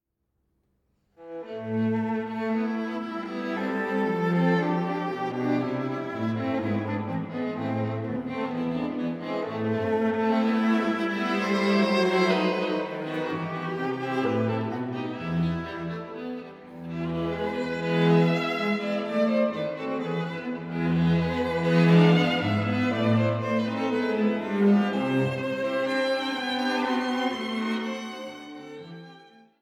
Quartett für 2 Violinen, Viola und Violoncello
Allegro